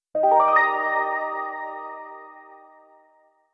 メニュー用効果音
決定音(18) タラリランン